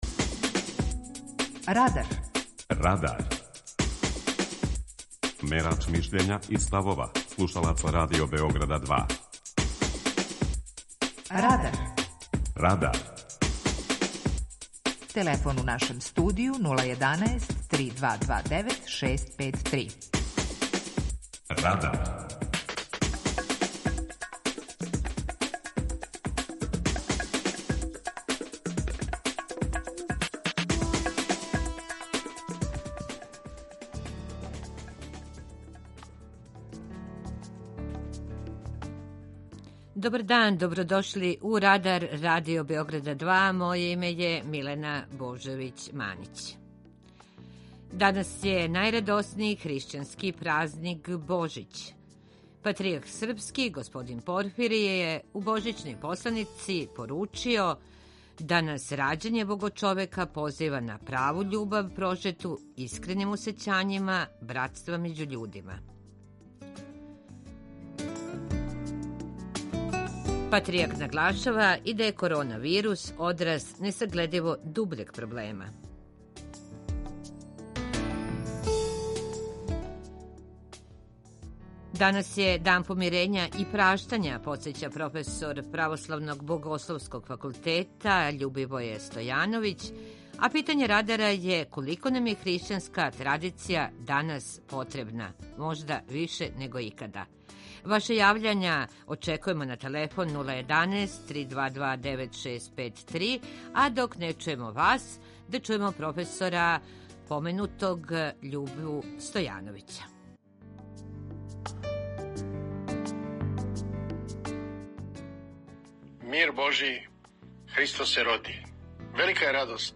Данас је најрадоснији хришћански празник Божић. преузми : 18.80 MB Радар Autor: Група аутора У емисији „Радар", гости и слушаоци разговарају о актуелним темама из друштвеног и културног живота.